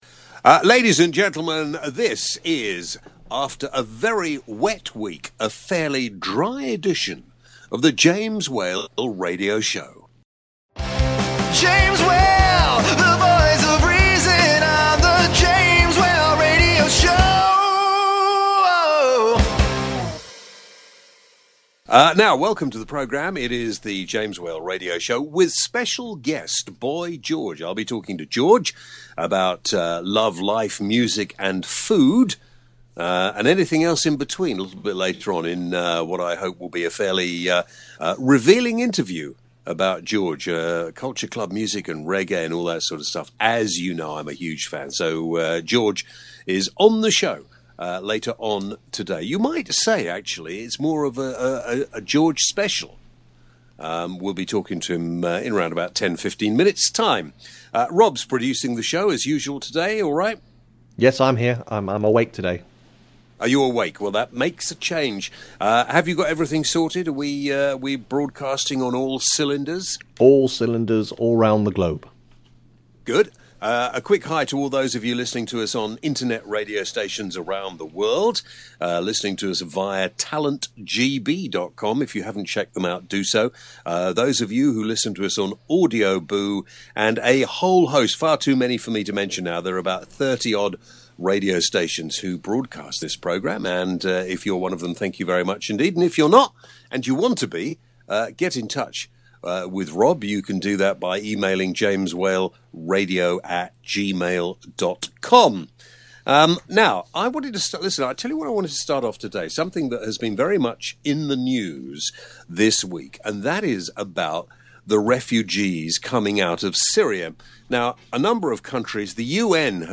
James chats to Boy George about his new track “My God” as well as Religion, Food, and Homophobia.